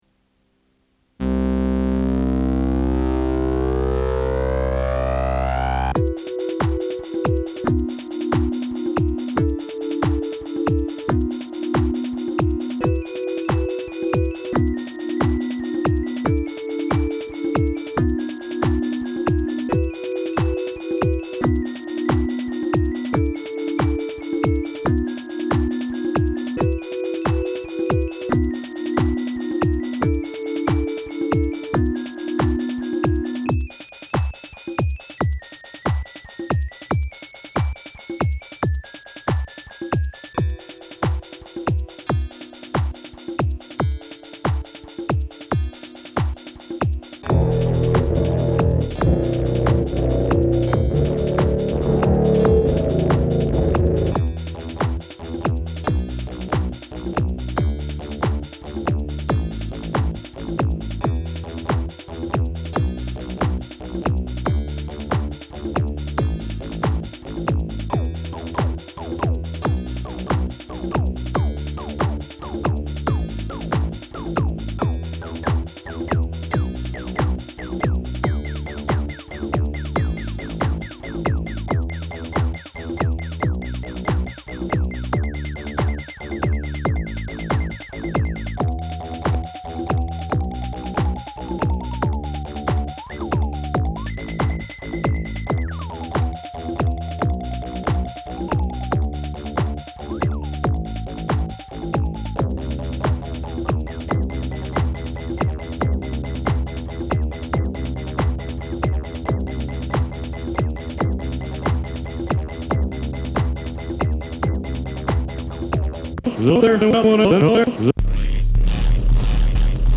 Early MIDI and sample experiments.